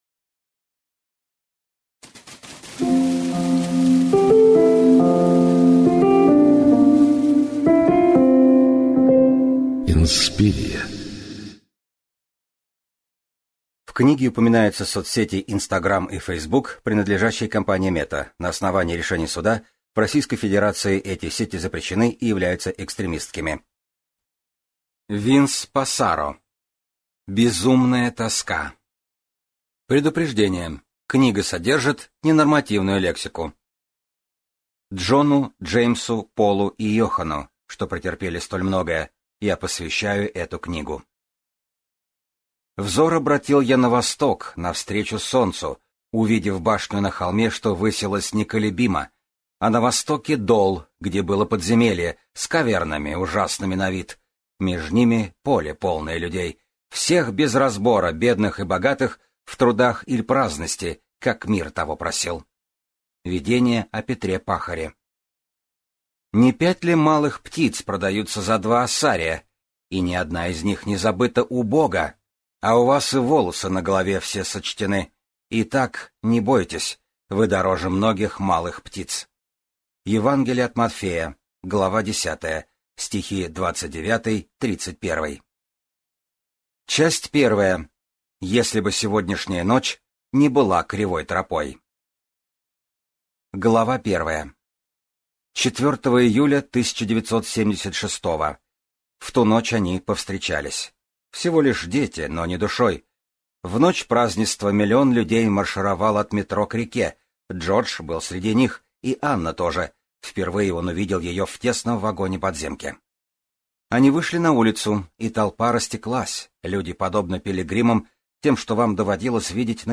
Аудиокнига Безумная тоска | Библиотека аудиокниг